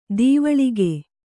♪ dīvaḷige